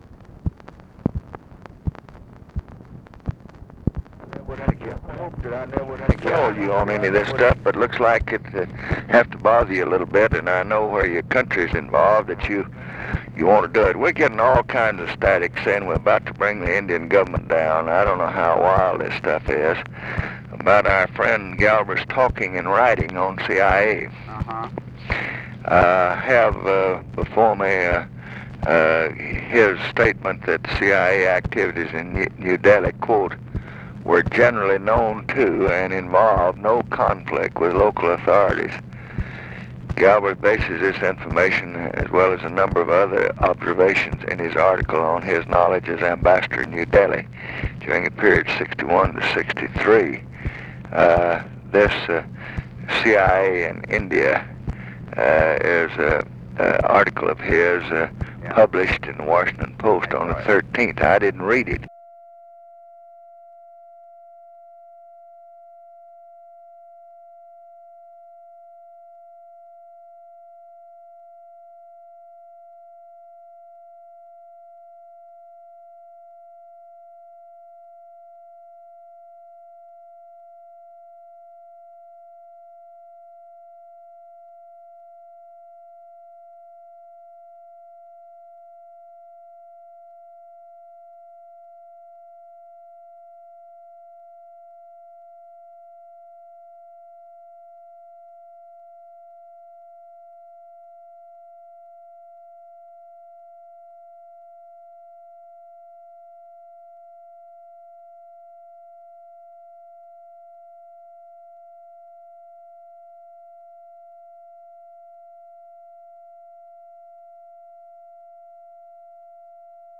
Conversation with MCGEORGE BUNDY, March 25, 1967
Secret White House Tapes